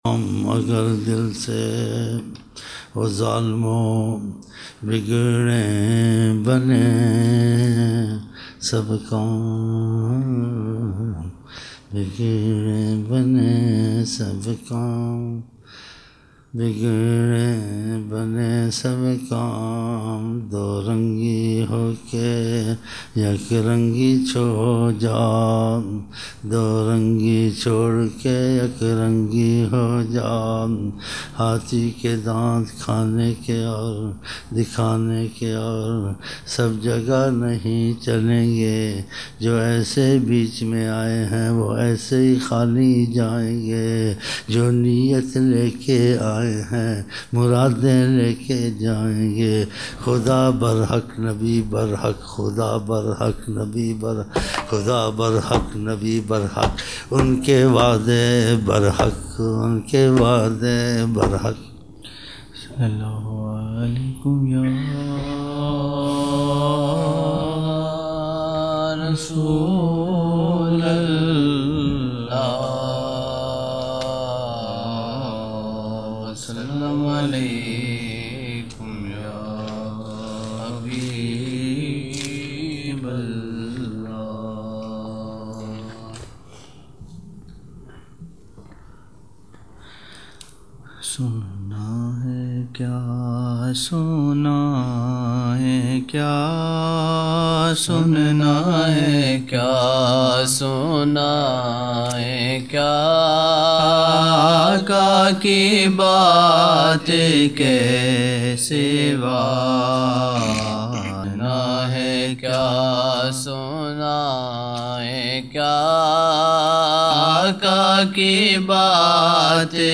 ظہر محفل